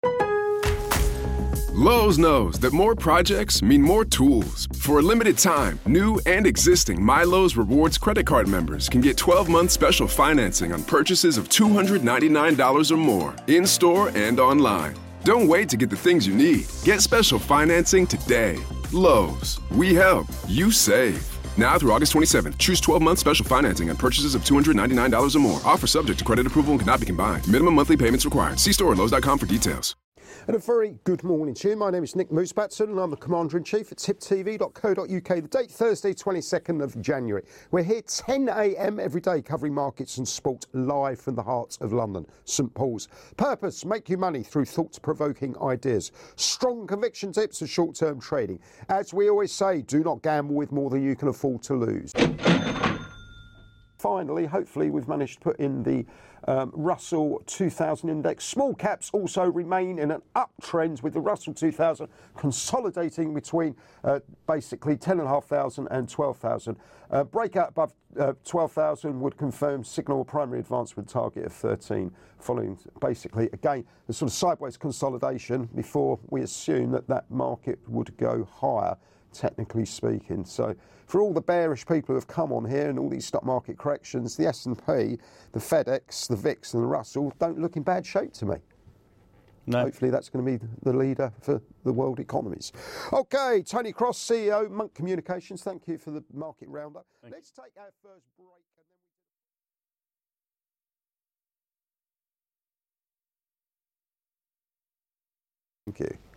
Live Market Round-Up & Soupbox thoughts